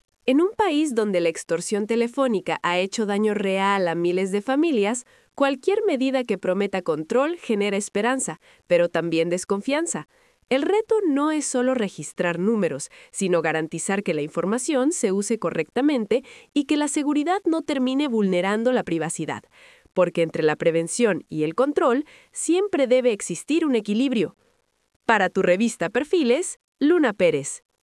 COMENTARIO EDITORIAL 🎙